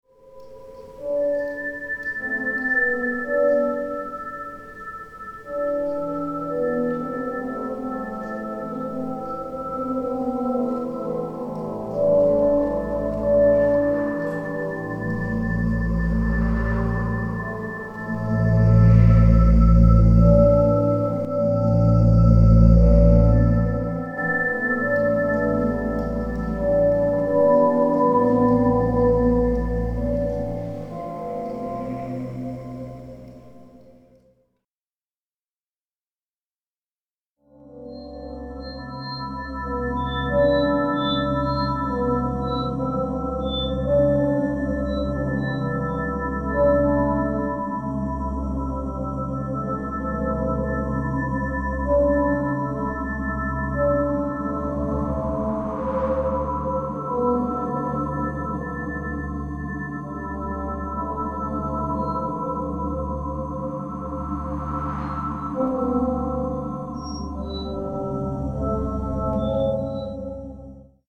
Live version
at Sonic Arts Research Centre, Queen’s University Belfast